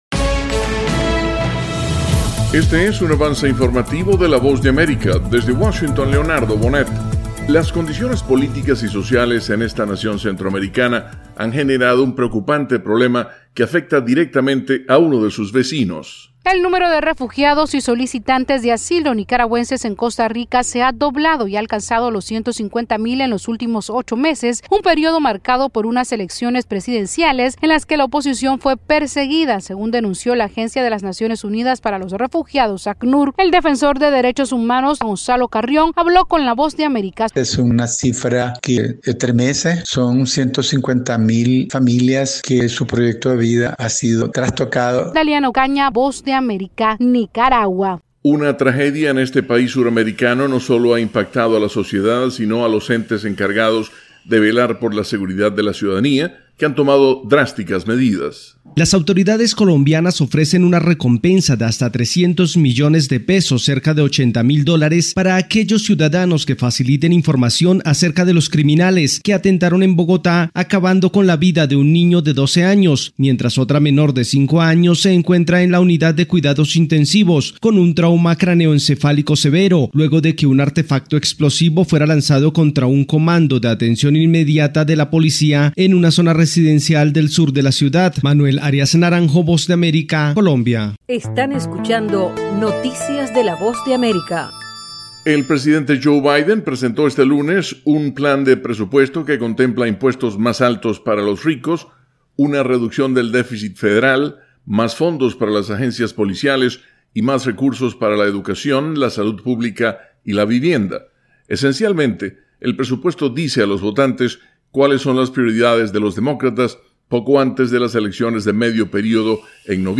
Última hora